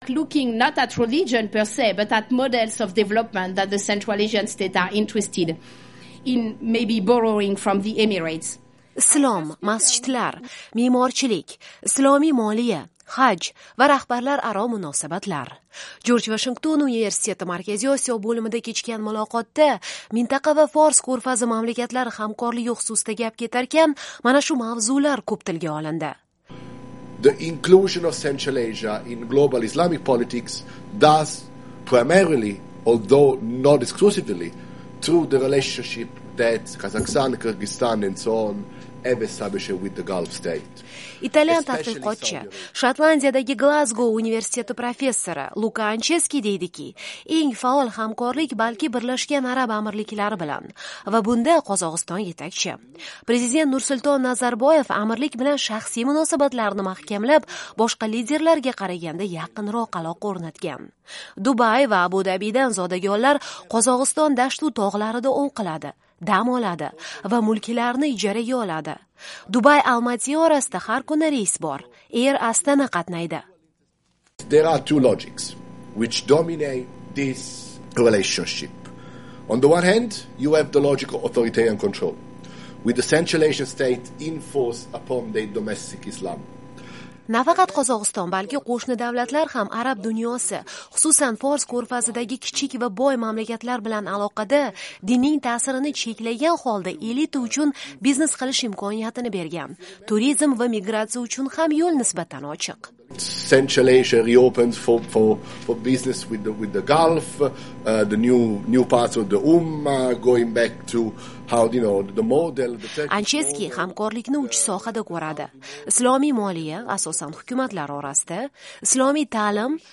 Islom, masjidlar, me'morchilik, islomiy moliya, haj va rahbarlararo munosabatlar. Jorj Vashington universiteti Markaziy Osiyo bo’limida kechgan muloqotda mintaqa va Fors ko’rfazi mamlakatlari hamkorligi xususida gap ketar ekan, mana shu mavzular ko’p tilga olindi.